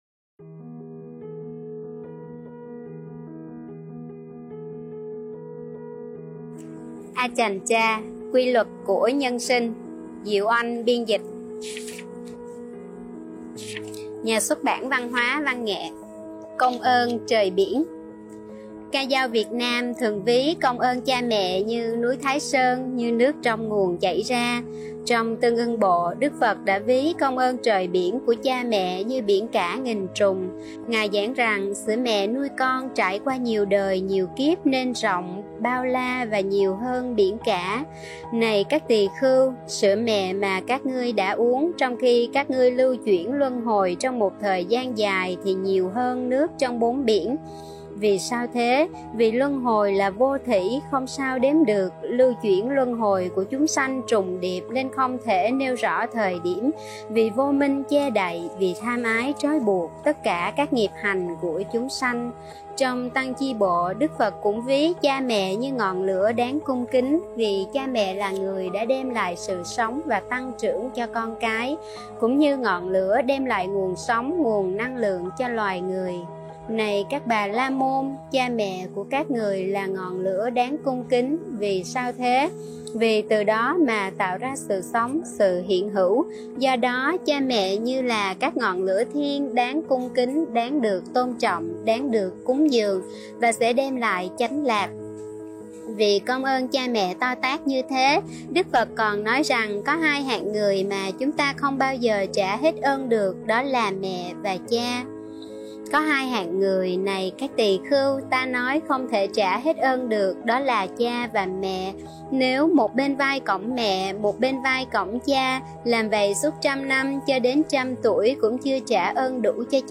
Sách nói Quy luật của nhân sinh - Ajahn Chah - Phần 1